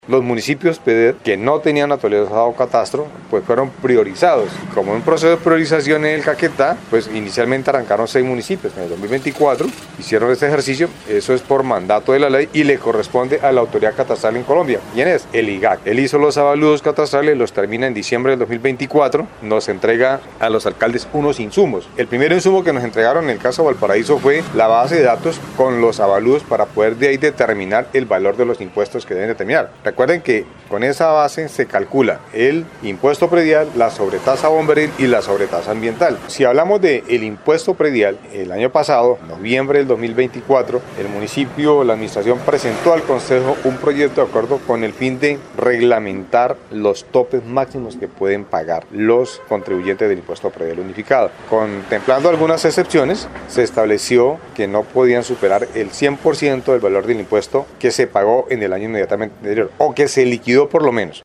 Arbenz Pérez Quintero, alcalde del municipio de Valparaíso, explicó que la actualización catastral, realizada por el IGAC, es un mandato gubernamental que viene desde la firma de los tratados de paz con la extinta guerrilla de las FARC.
ALCALDE_ARBENZ_PEREZ_CATASTRO_-_copia.MP3